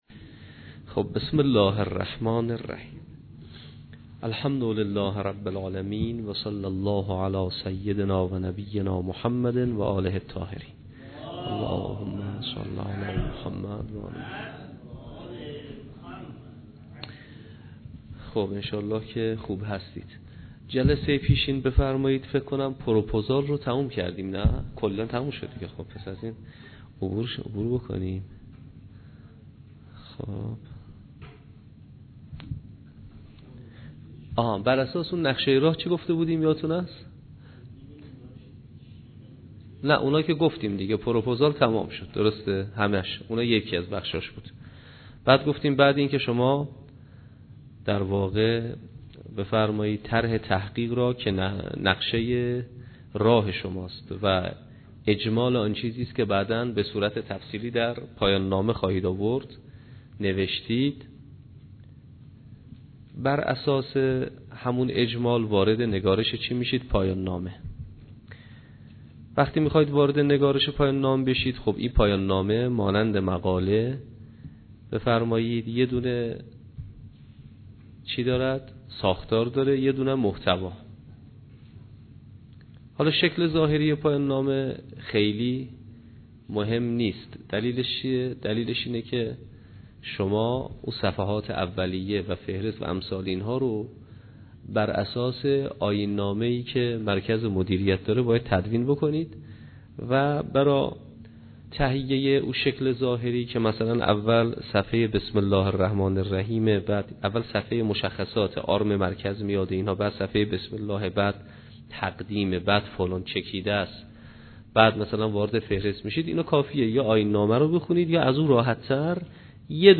جلسه اول کارگاه پایان نامه نویسی